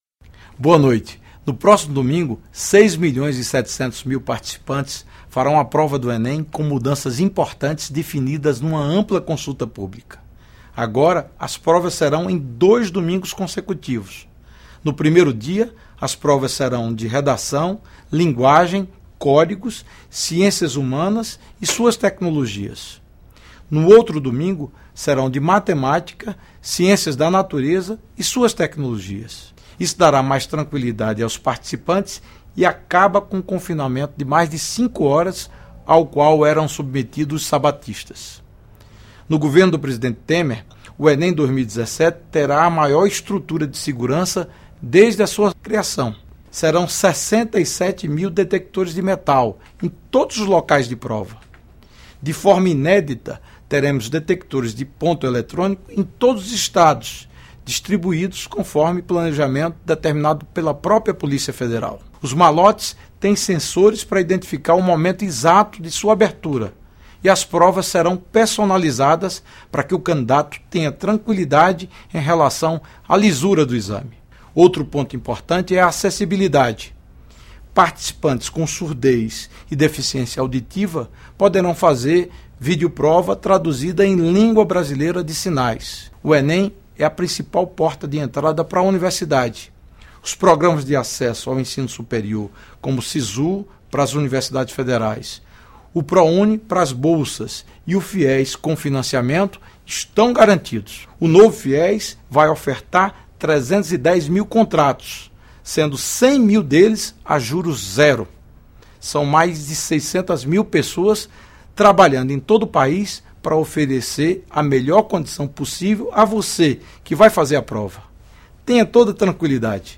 Pronunciamento do Ministro da Educação Mendonça Filho